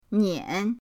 nian3.mp3